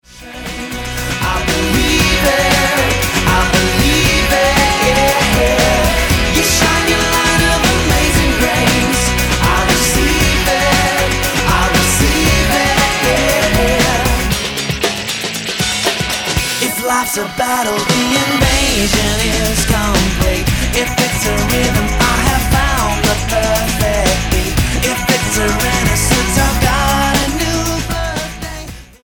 STYLE: Rock
Timelessly catchy pop-rock.